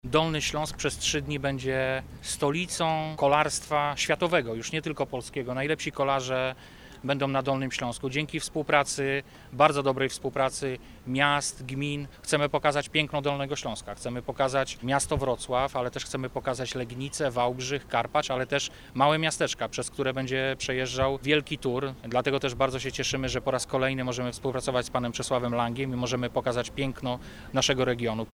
Tour de Pologne - konferencja prasowa
Jak miasto i region są przygotowane na organizację wydarzenia? Mówi Wojciech Bochnak, Wicemarszałek Województwa.